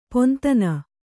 ♪ pontana